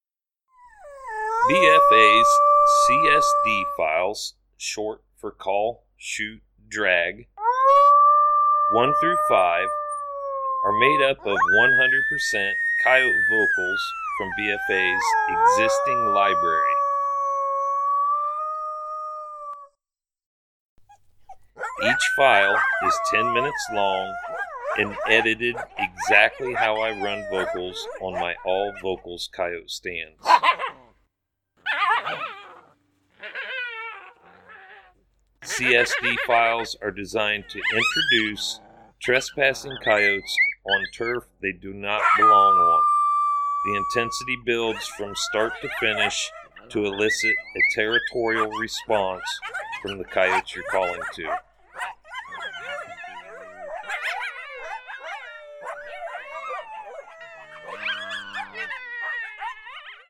Each BFA CSD File is 10 minutes in length, made up from our most popular Coyote Howls, Coyote Social Vocalizations and Coyote fights.